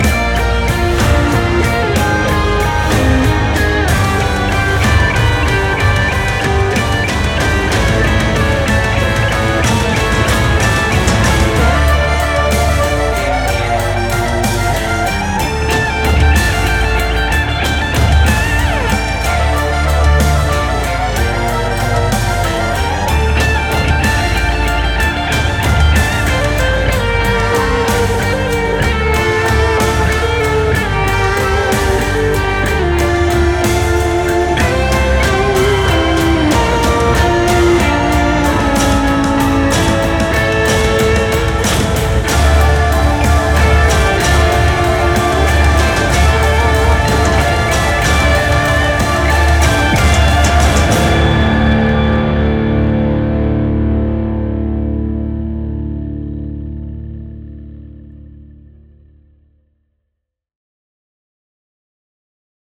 Electric Guitar, Drums
We both knew we accomplished our goal since the track ROCKS!